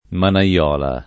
vai all'elenco alfabetico delle voci ingrandisci il carattere 100% rimpicciolisci il carattere stampa invia tramite posta elettronica codividi su Facebook manaiola [ mana L0 la ] (lett. manaiuola [ mana LU0 la ]) s. f.